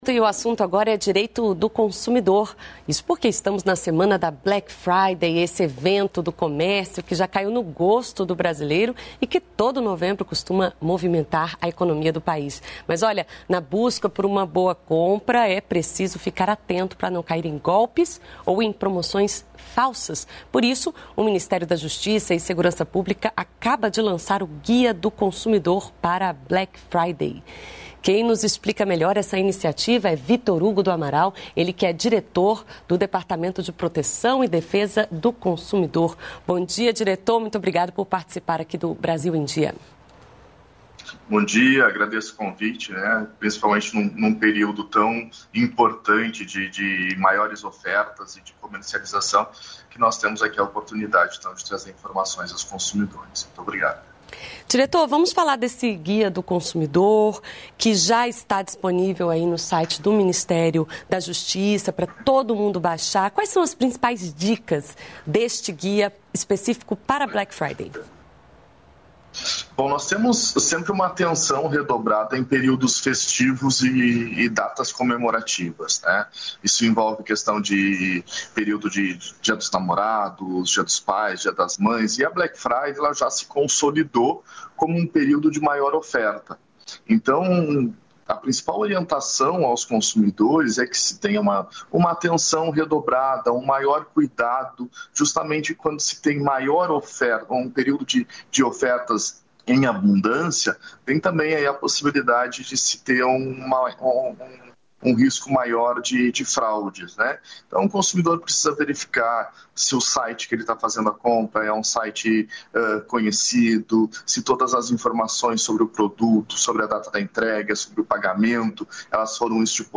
Brasil em Dia - Entrevista